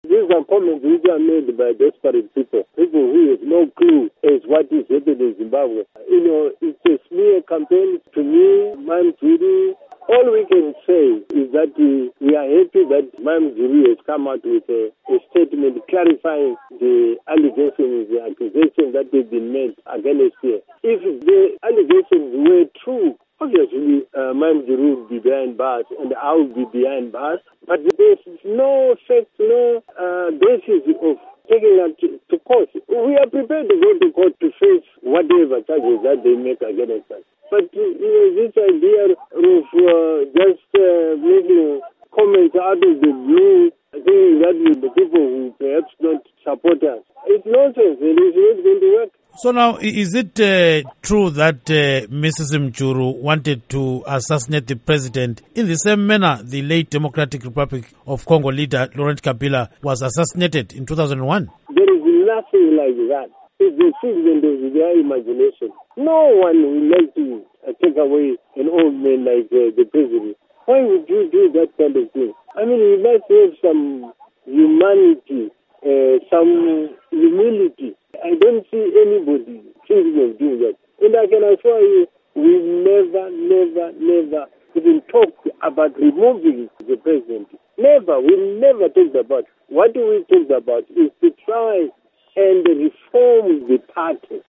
Interview With Rugare Gumbo on Mugabe Assassination Claims